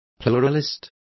Complete with pronunciation of the translation of pluralist.